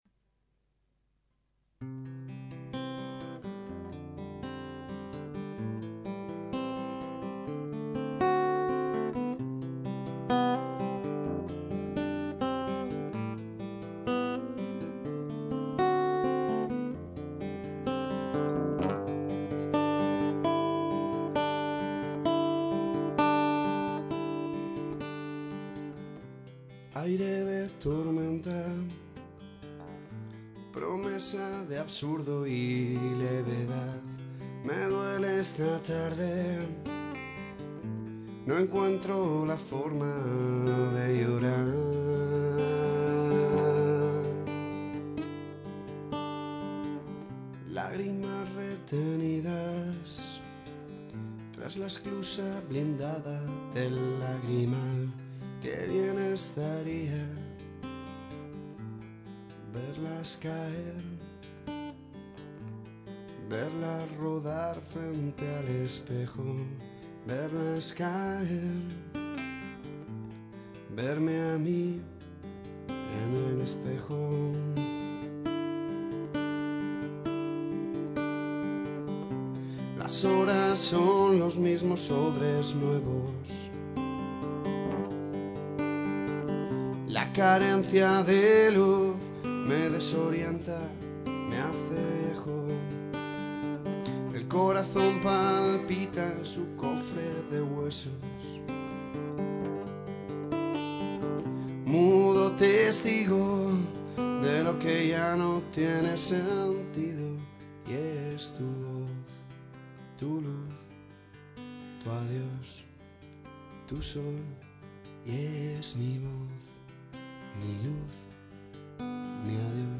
(y perdón por todo, no pasó ni media hora desde que empecé a hacerla hasta que la tuve grabada, mejorará con el tiempo).